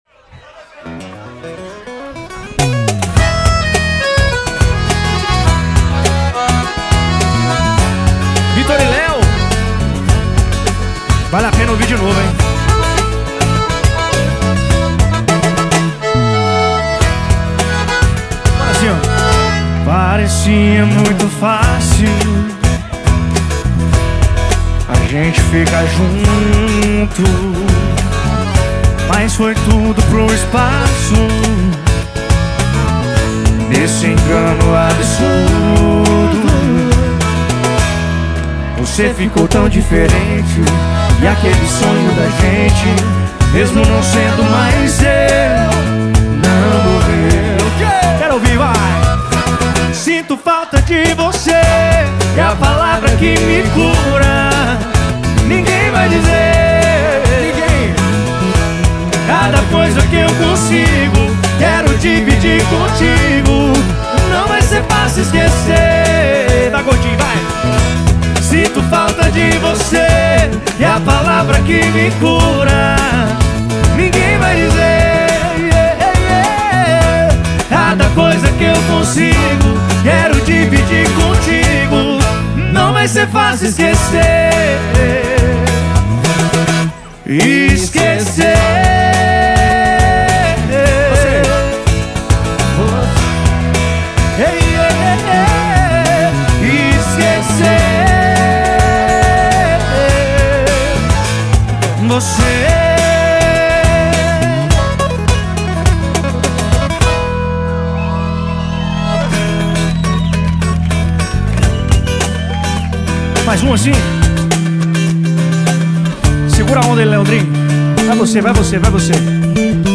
EstiloSertanejo